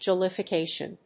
音節jol・li・fi・ca・tion 発音記号・読み方
/dʒὰləfɪkéɪʃən(米国英語), dʒ`ɔləfɪkéɪʃən(英国英語)/